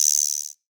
BGUIROSYNL20.wav